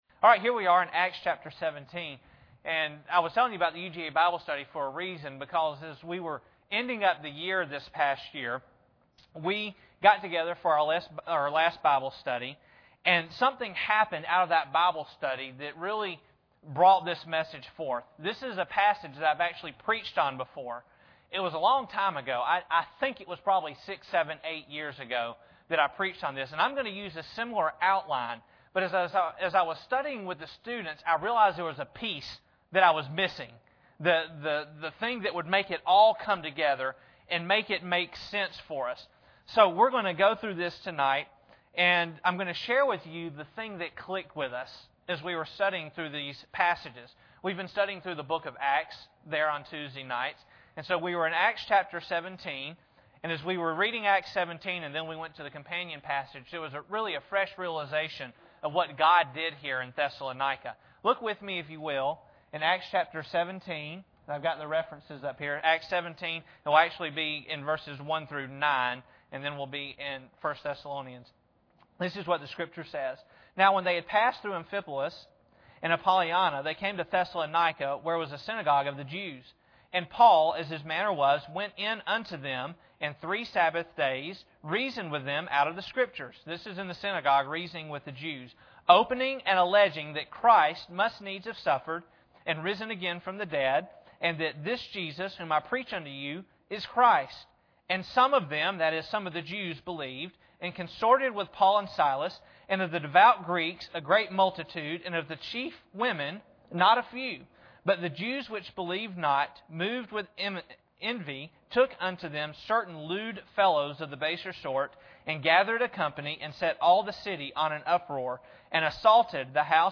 Acts 17:1-9 Service Type: Sunday Evening Bible Text